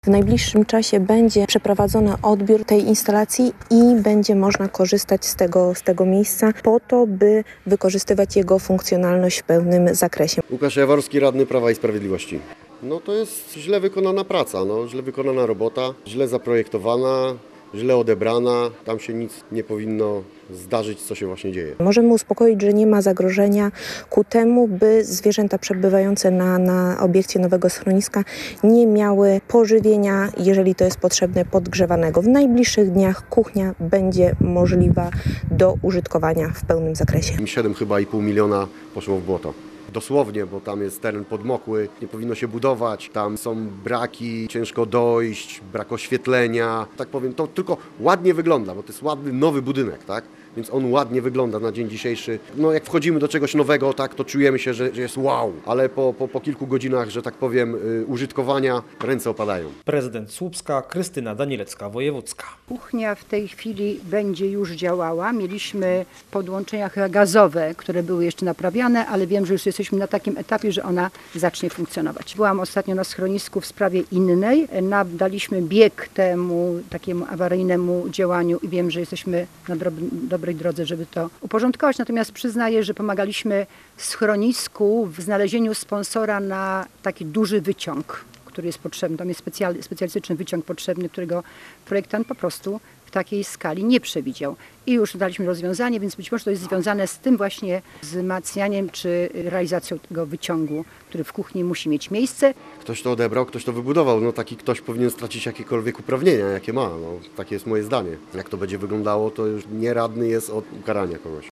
Posłuchaj materiału reportera Radia Gdańsk: